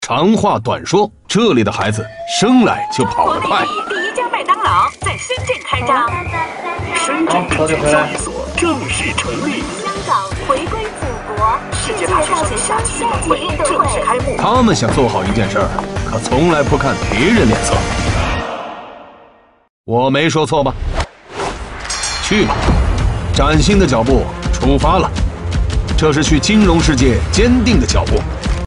男3号（品质、洪亮）
男3-深圳人才局宣传片.mp3.mp3